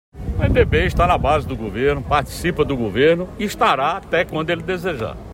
Sem ‘meias palavras’, o chefe do executivo foi incisivo ao responder as indagações da imprensa e foi direto: “O MDB está na base do Governo, participa do Governo e estará até quando ele desejar”.
As declarações do governador João Azevêdo foram ao lado do ex-governador e Secretário de Estado, Roberto Paulino e do deputado estadual Raniery Paulino.